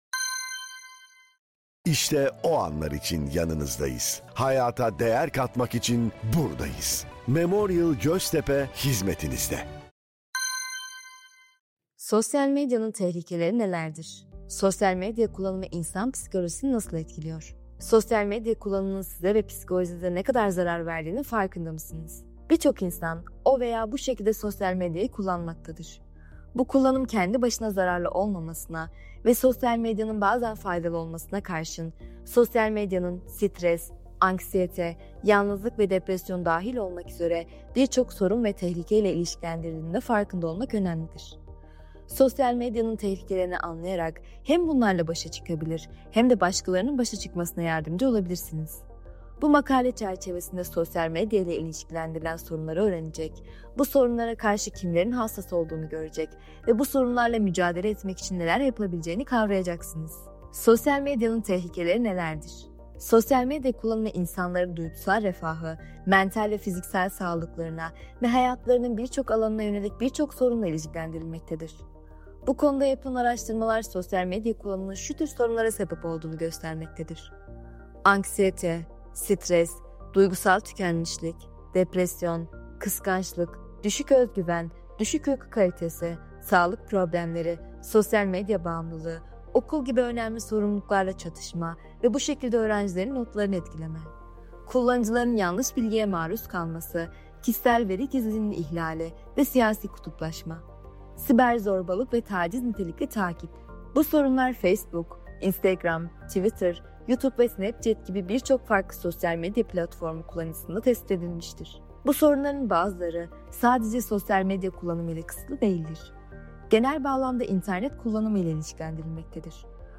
Bu yayın listemizde, Evrim Ağacı'nda yayınladığımız içeriklerden seçilmiş yazılarımızı yazarlarımızın kendileri, diğer yazarlarımız veya ses sanatçıları seslendirerek, sizlerin kulaklarına ulaştırıyor.